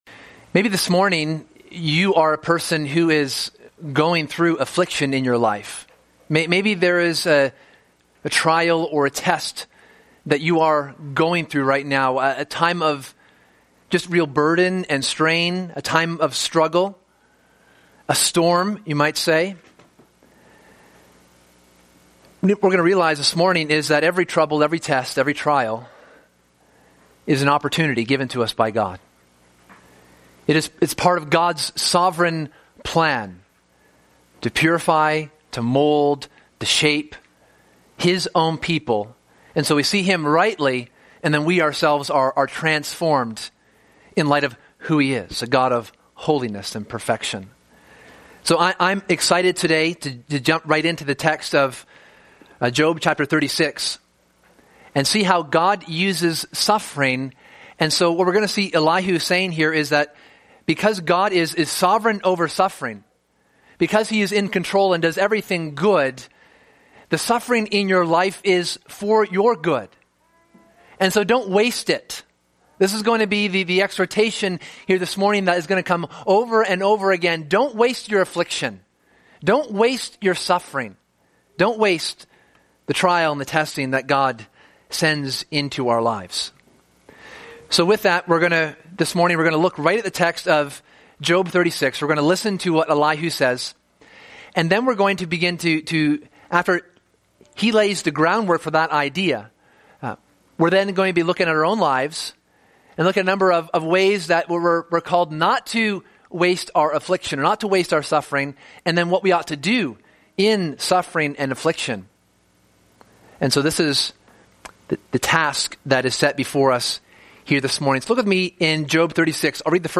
This book, and thus this sermon series, explores the nature and character of God.